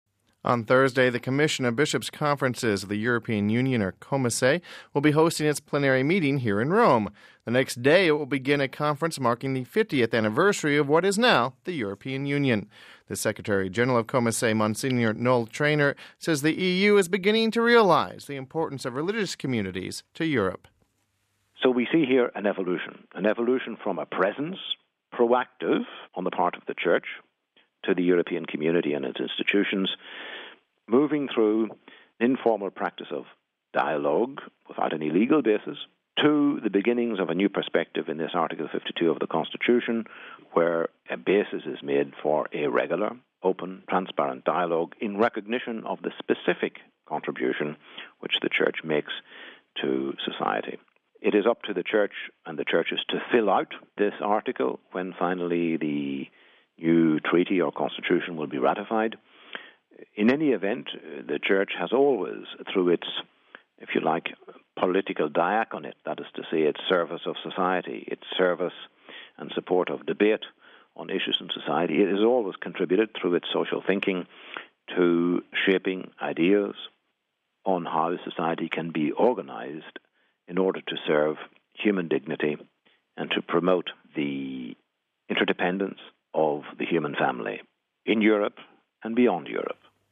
Home Archivio 2007-03-20 18:51:40 COMECE meeting to be held in Rome On Thursday, the Comission of Bishop’s Conferences of the European Union, or COMECE, will be hosting its plenary meeting here in Rome. We have this report...